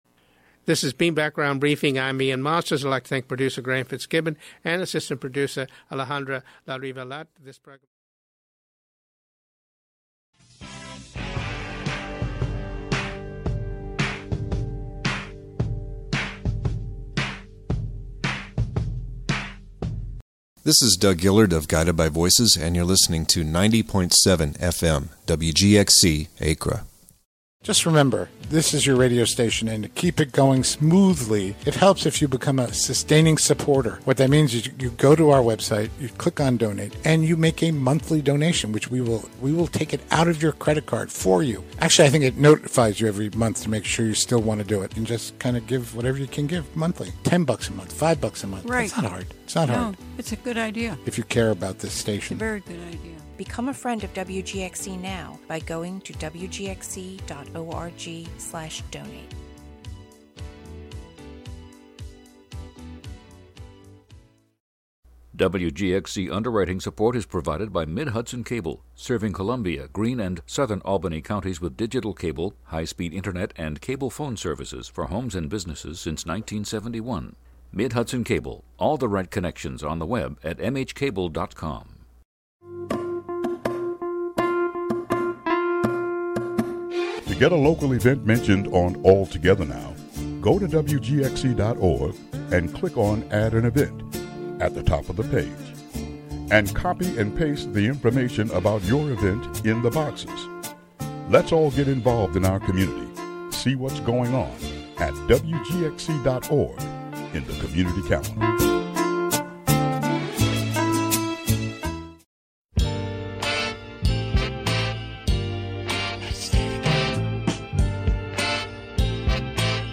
Tune in an excerpt of a meeting held by the Vera Institute of Justice about the Greene County Jail from July 9, and also a news report from The Sanctuary for Independent Media's WOOC-LP about the immigration protest July 6 in Albany. "All Together Now!" is a daily news show brought to you by WGXC-FM in Greene and Columbia counties.